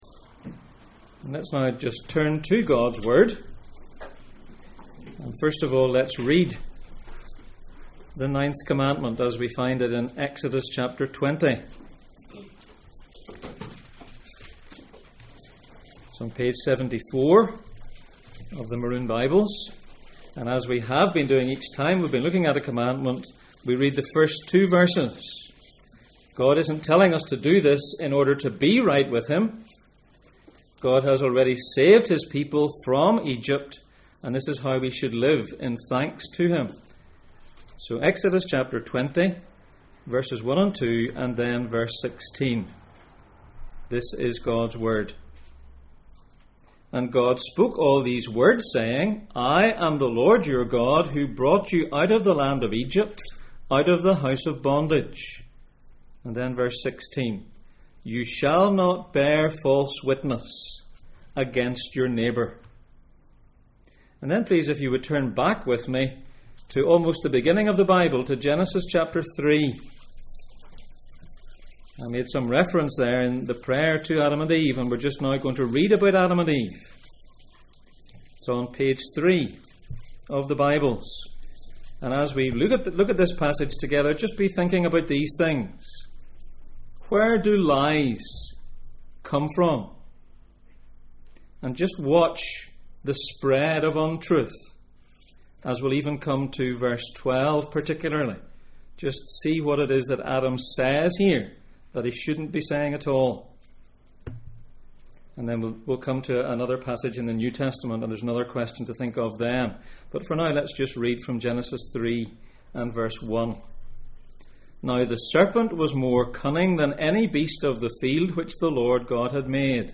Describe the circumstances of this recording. Gods instructions for life Passage: Exodus 20:1-2, Exodus 20:16, Genesis 3:1-13, John 8:37, John 8:44-47 Service Type: Sunday Morning